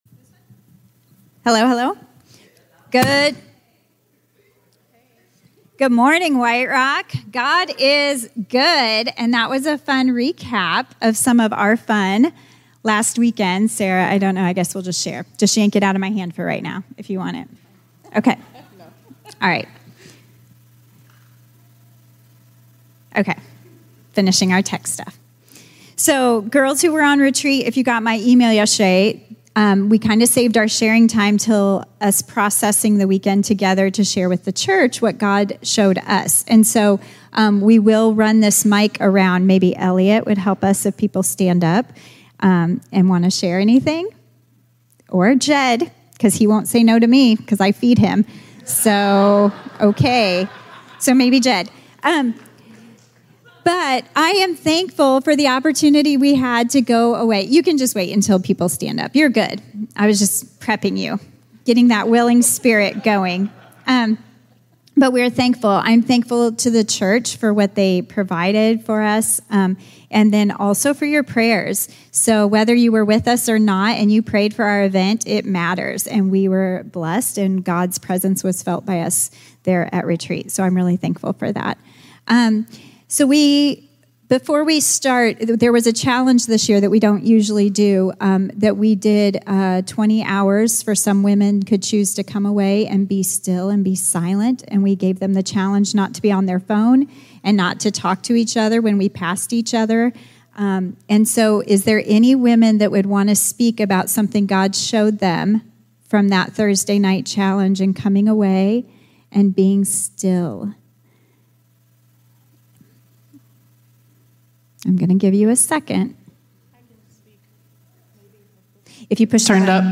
Sermons | White Rock Fellowship